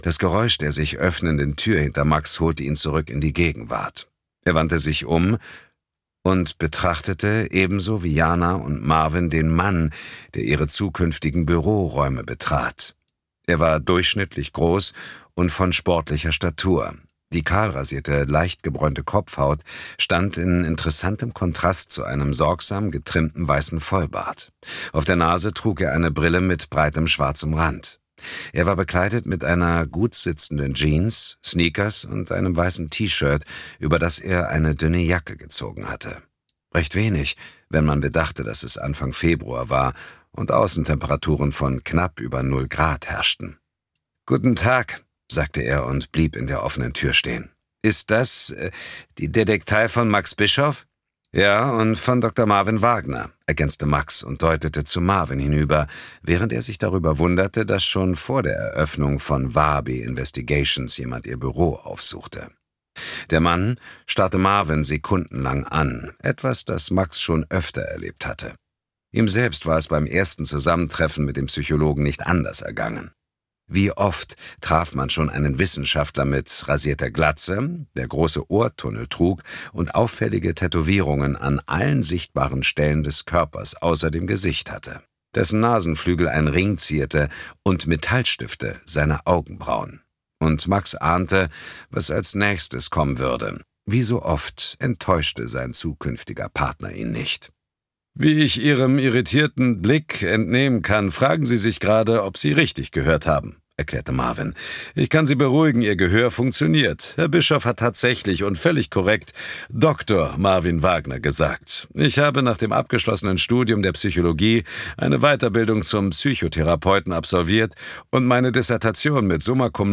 Rezension: Dietmar Wunder liest Arno Strobel – Mörderfinder – Das Muster des Bösen
2025 Argon Hörbuch
Interpret: Dietmar Wunder
Dietmar Wunder ist mit seiner kraftvollern Baritonstimme und als Synchronsprecher von James-Bond-Darsteller Daniel Craig Spannungsprofi durch und durch.
Gleich dieser Gruseleffekt am Anfang nimmt einen sofort gefangen und man muss einfach weiterhören.
Mit seiner markanten, sehr wandlungsfähigen Stimme ist er ein äußerst beliebter Hörbuchsprecher, der nuancenreich Spannung vermitteln kann.